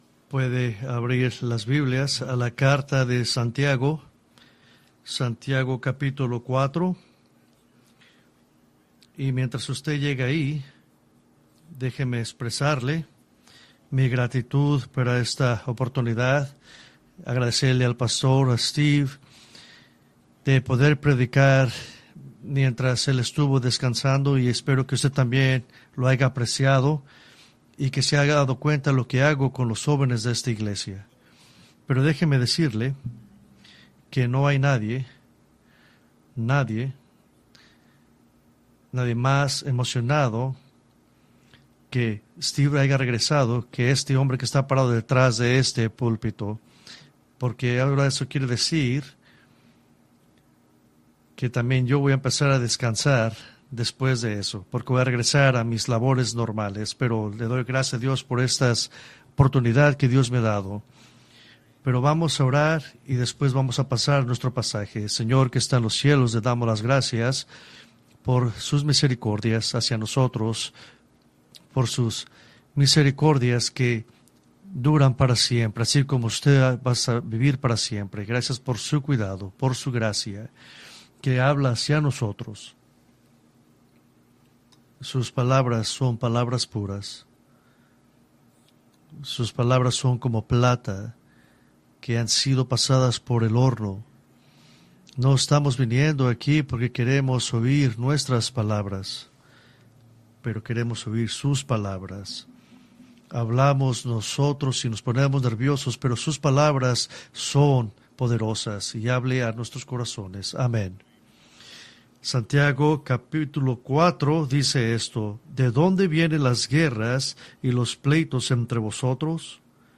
Preached August 18, 2024 from James 4:6-10